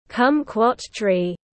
Kumquat tree /ˈkʌm.kwɒt triː/